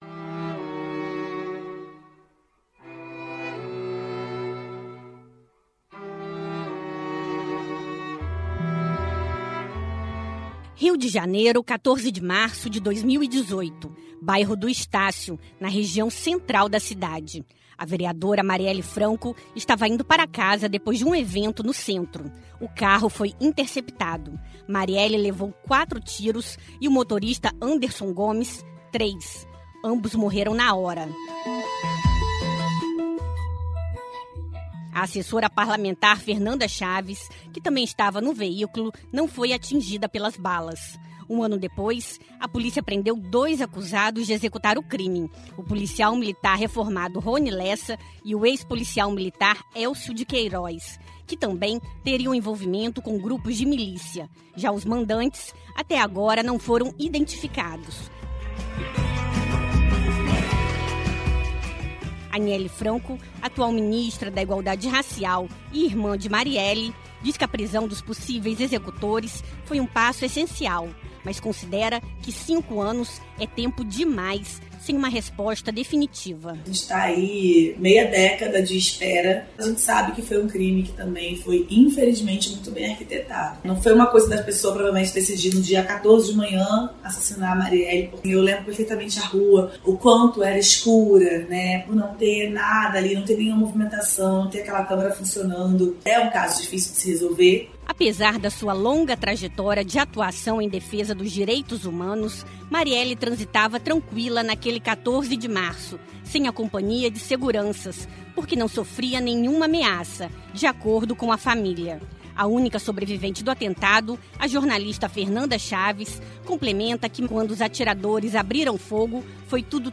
Anielle Franco, atual ministra da Igualdade Racial e irmã de Marielle, diz que a prisão dos possíveis executores foi um o essencial, mas considera que cinco anos é tempo demais sem uma resposta definitiva: